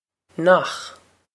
nach nokh
This is an approximate phonetic pronunciation of the phrase.